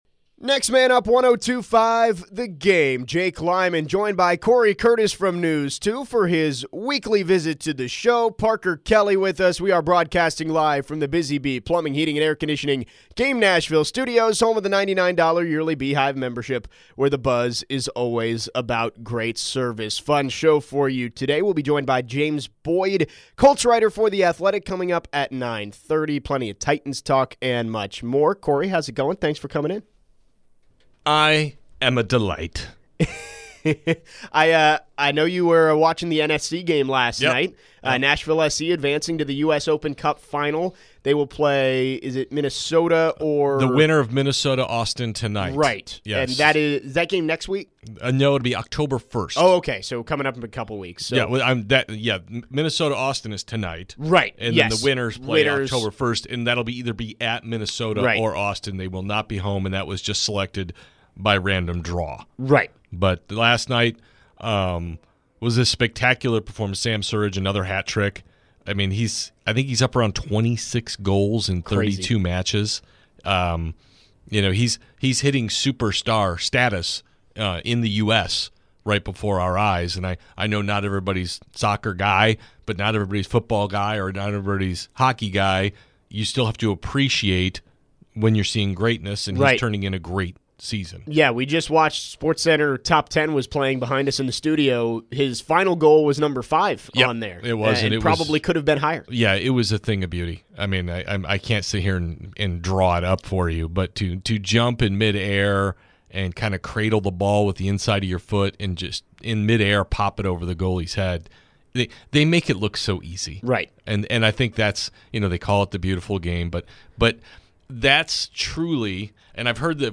in studio today. The guys preview Titans and Colts coming up this weekend.